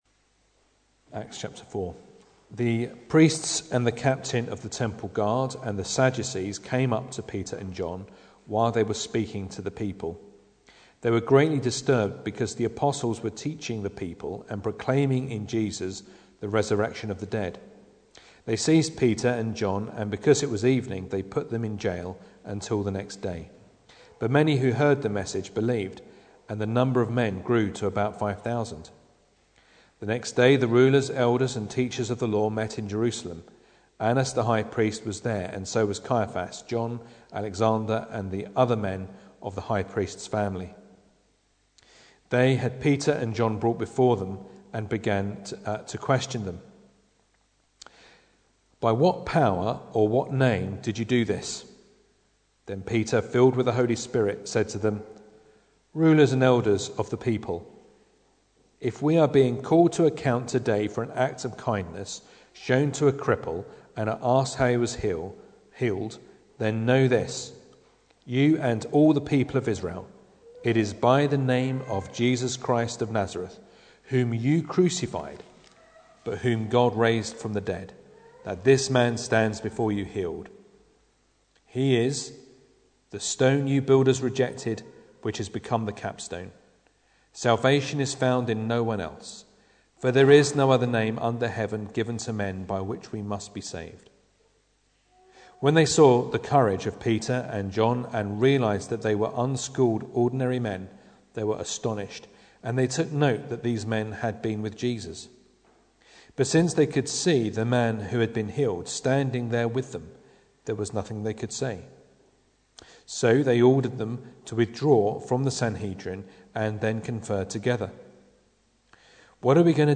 Acts 4:1-22 Service Type: Sunday Evening Bible Text